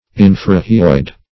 Search Result for " infrahyoid" : The Collaborative International Dictionary of English v.0.48: Infrahyoid \In`fra*hy"oid\, a. [Infra + hyoid.]
infrahyoid.mp3